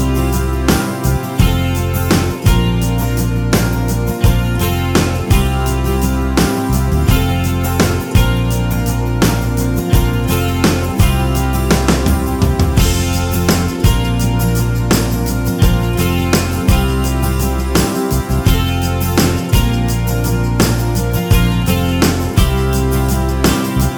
Minus Guitars Pop (1980s) 4:27 Buy £1.50